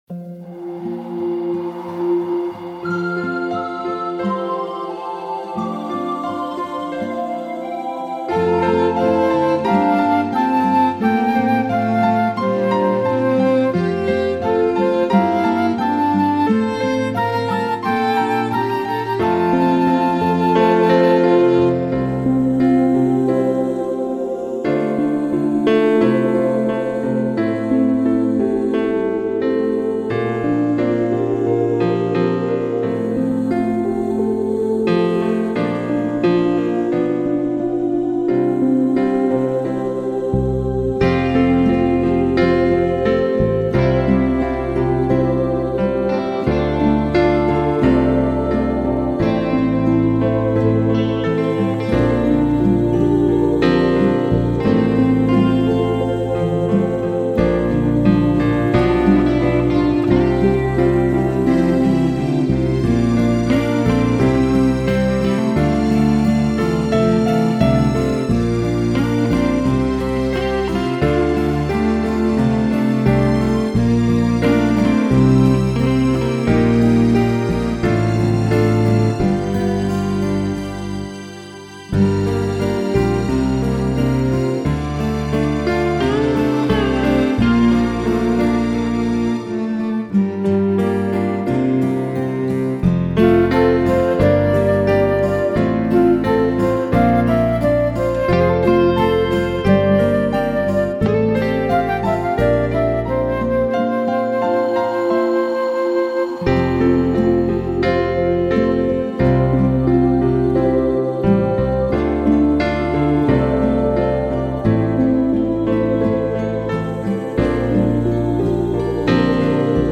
beside-me-always-6-arrangement.mp3